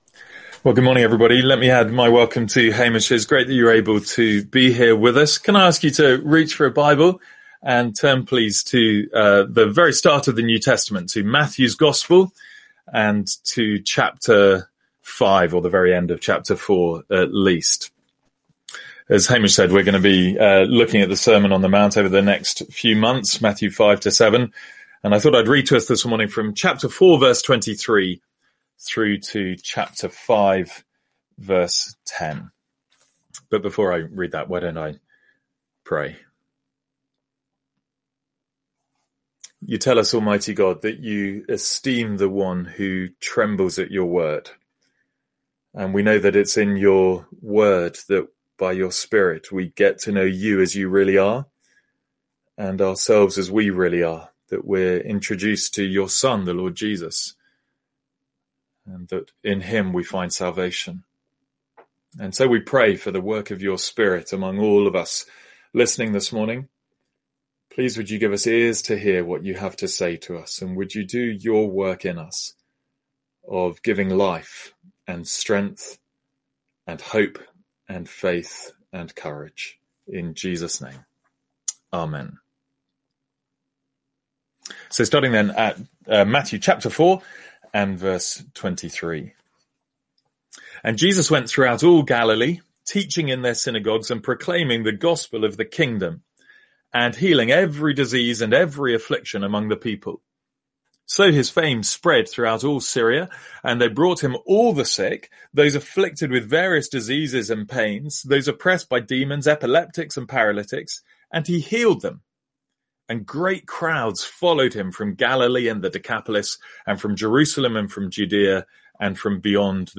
Sermons | St Andrews Free Church
From our morning service in the Sermon on the Mount.